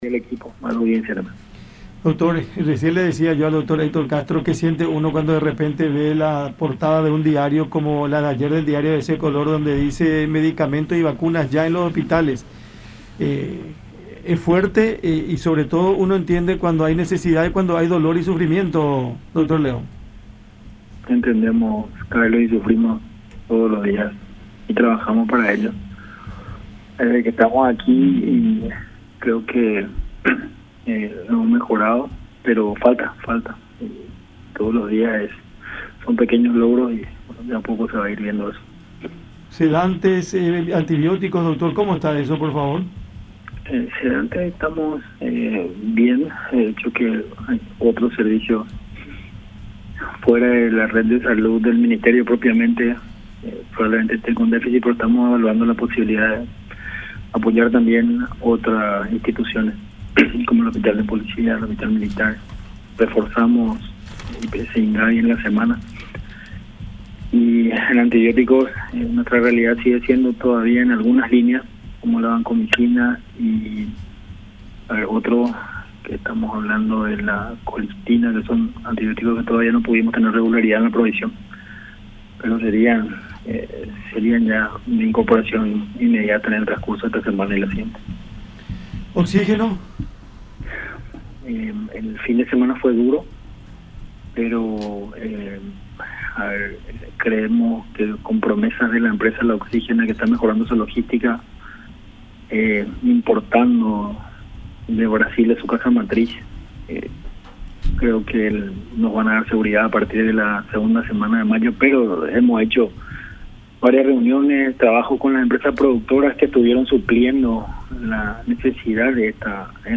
“Fue un fin de semana duro en cuanto a oxígeno, pero se estuvo supliendo esa provisión, aún cuando la empresa proveedora, La Oxígena, haya mejorado su logística”, dijo León en conversación con La Unión, aseverando que, según informó la firma, se mejorará en la entrega del producto a la cartera sanitaria desde mediados del próximo mes.